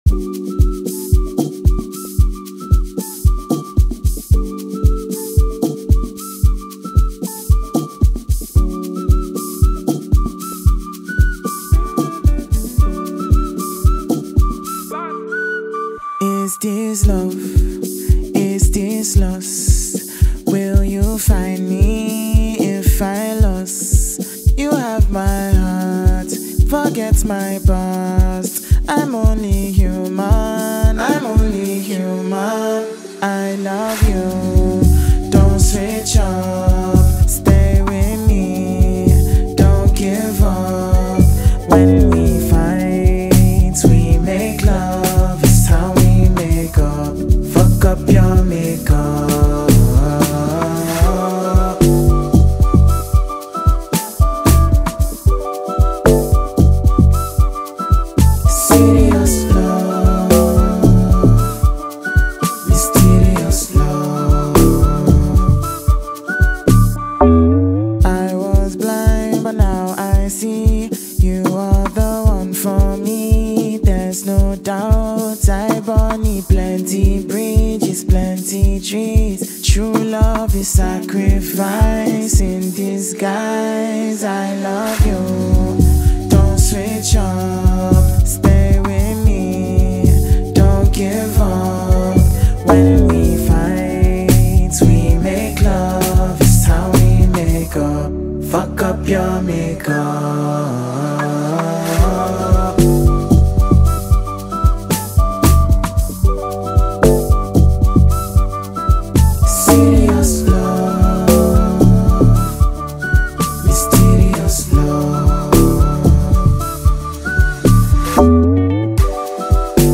a high Tempo Afrobeats song with an infusion of Ojapiano